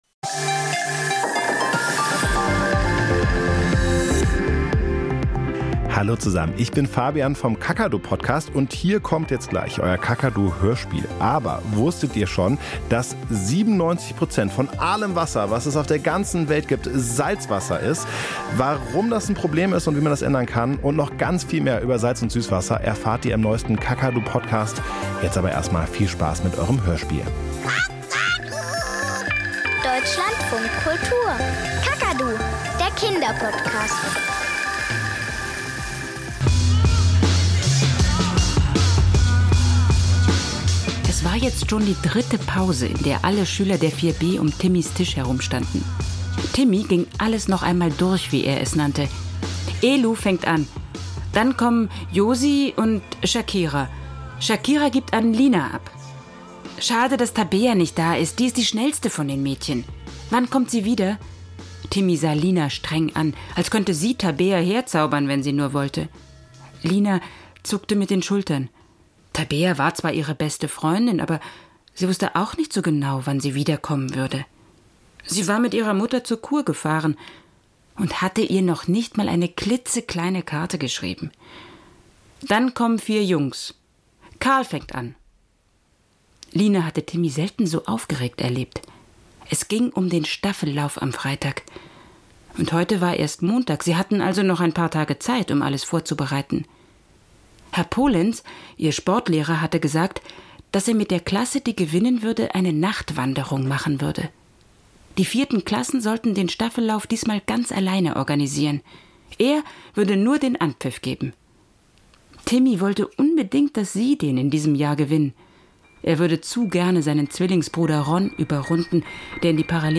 Kinderhörspiel und Geschichten - Staffellauf